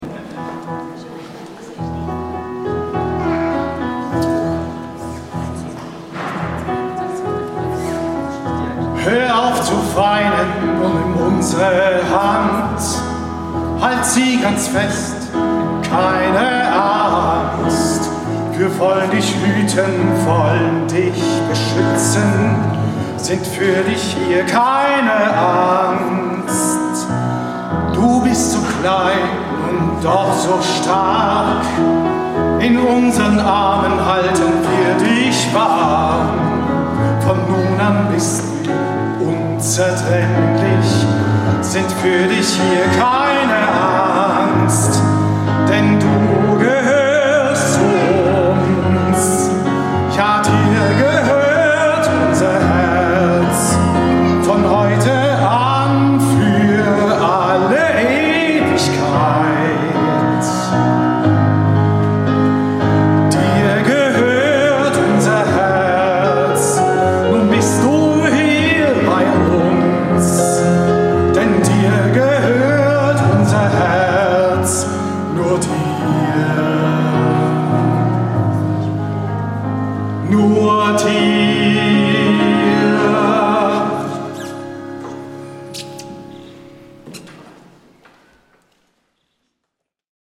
Live-Mitschnitte: